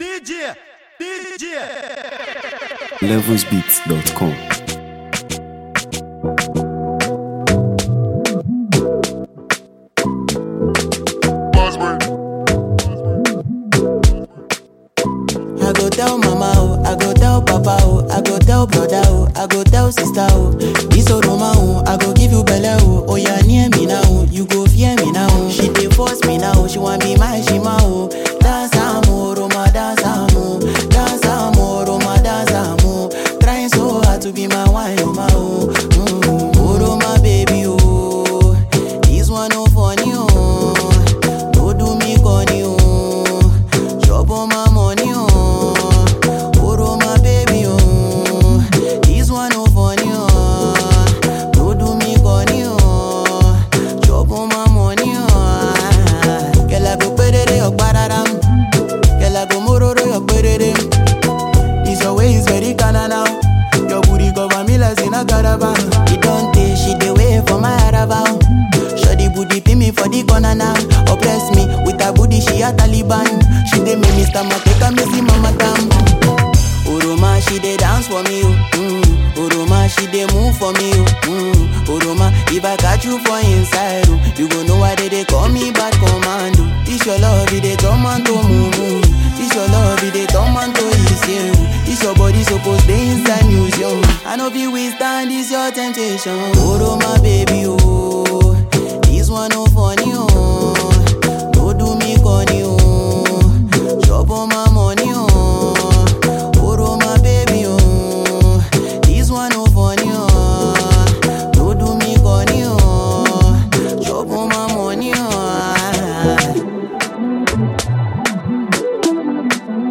vibrant and feel-good record